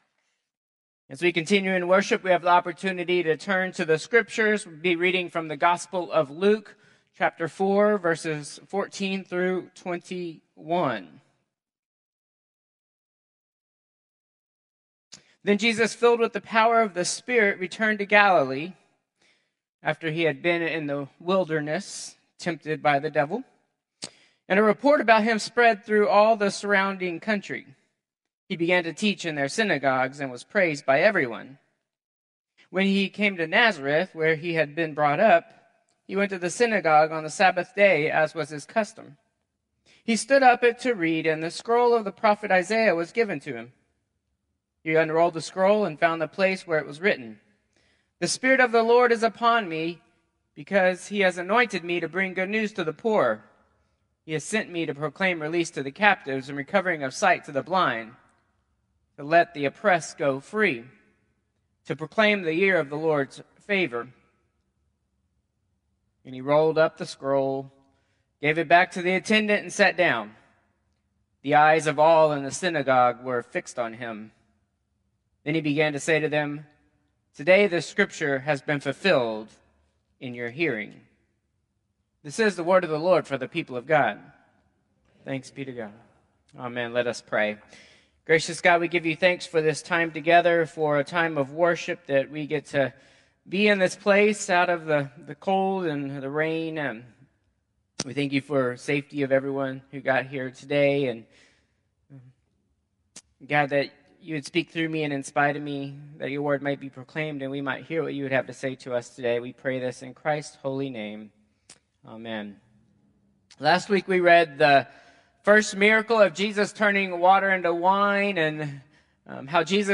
Copy of Contemporary Service 1/26/2025